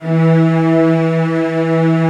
CELLOS F#3-L.wav